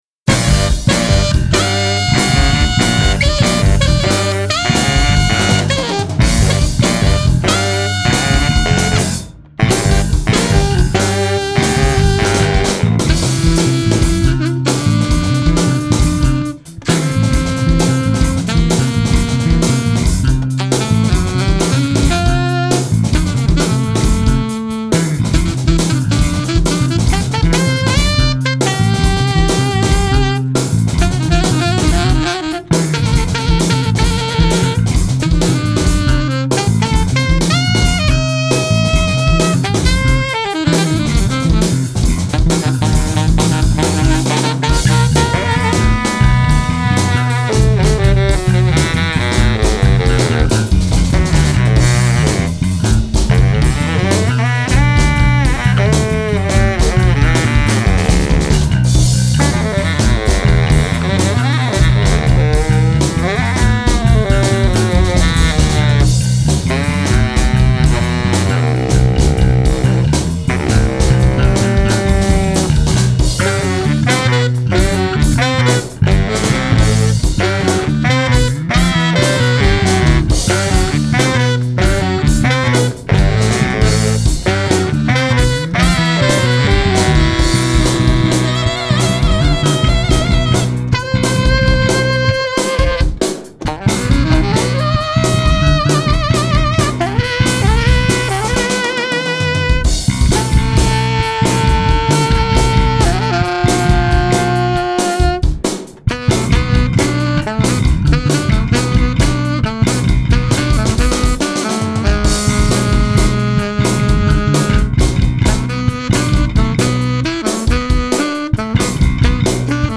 genre: art-funk
jazzy-funky sounds